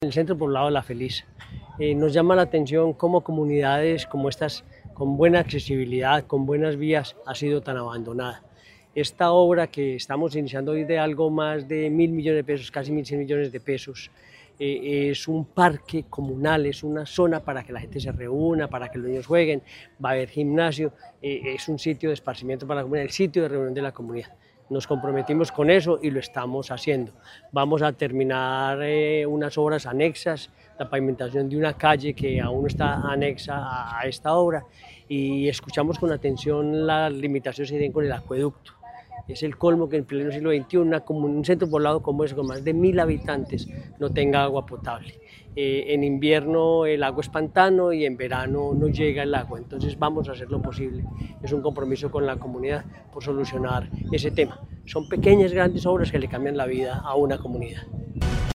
Gobernador de Caldas, Henry Gutiérrez Ángel.
Henry-Gutierrez-Angel.mp3